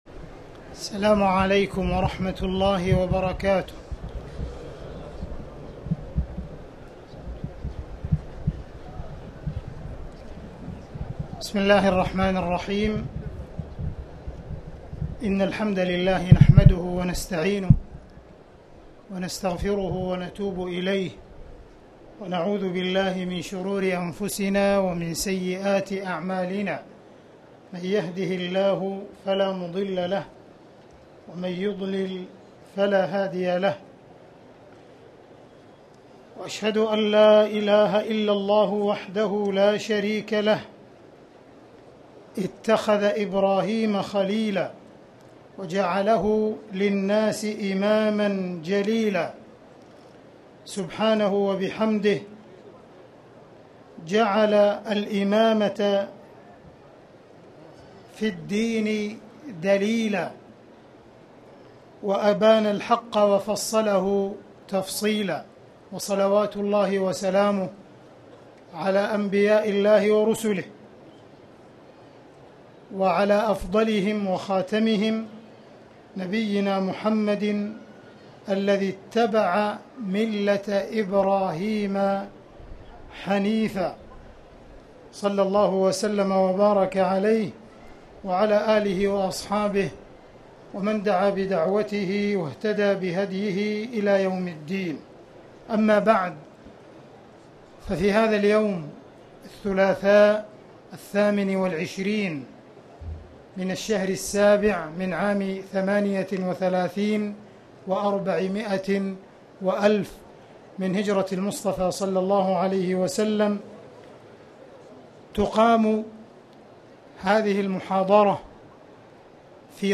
تاريخ النشر ٢٨ رجب ١٤٣٨ المكان: المسجد الحرام الشيخ: معالي الشيخ أ.د. عبدالرحمن بن عبدالعزيز السديس معالي الشيخ أ.د. عبدالرحمن بن عبدالعزيز السديس القدوة الحسنة